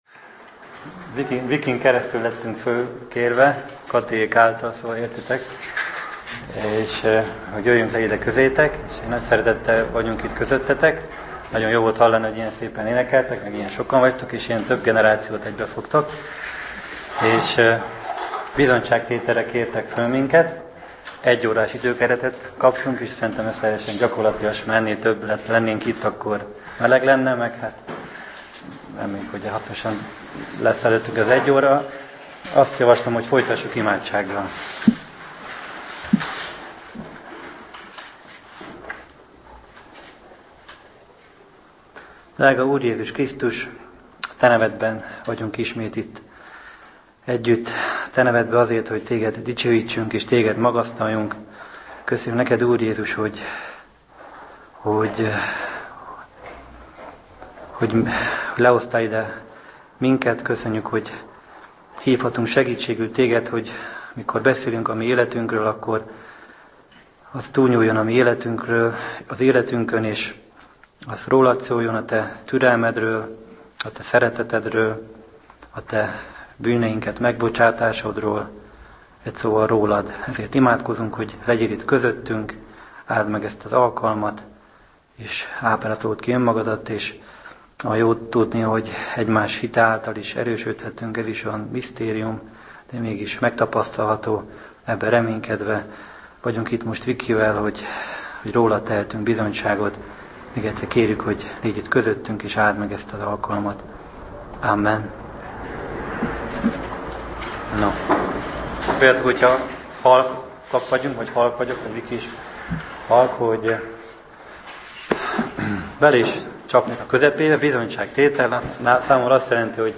Bizonyságtétel